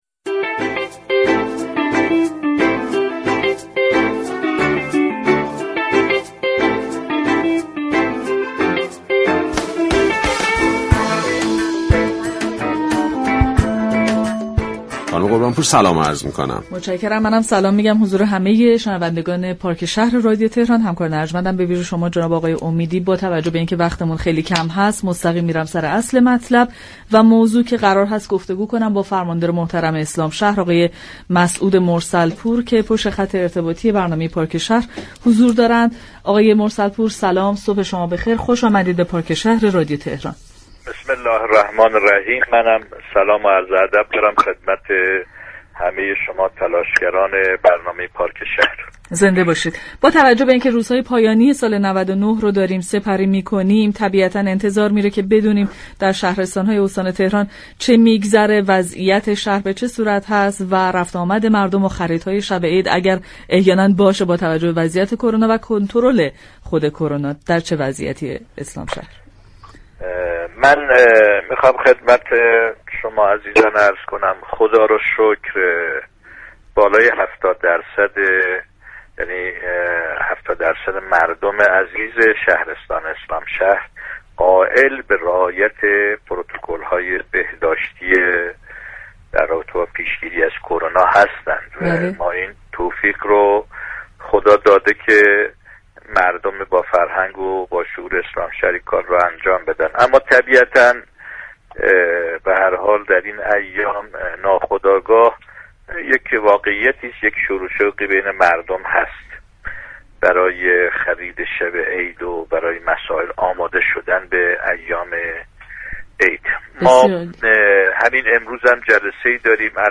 مسعود مرسل پور فرماندار اسلامشهر در گفتگو با این برنامه صبحگاهی درباره تمهیدات فرمانداری این شهرستان در پیشگیری از شیوع بیشتر كرونا اظهار داشت